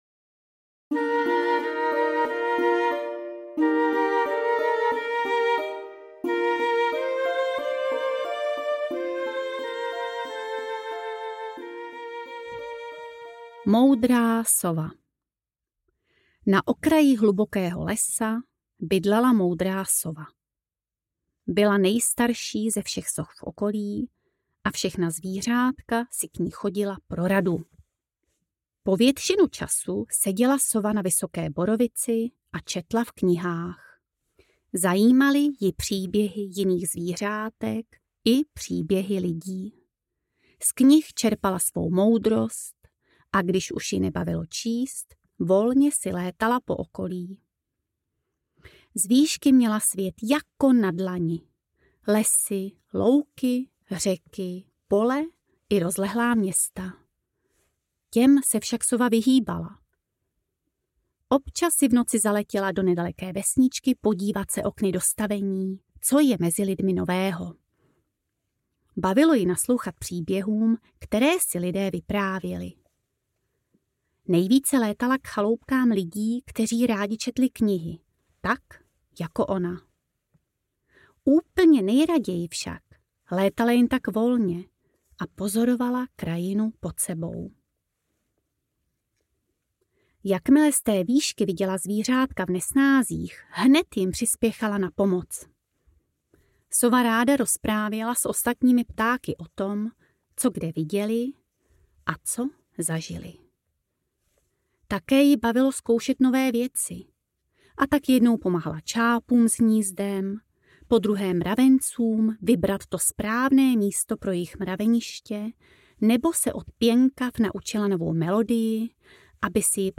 Čakrové pohádky audiokniha
Ukázka z knihy
cakrove-pohadky-audiokniha